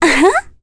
Ophelia-vox-Laugh.wav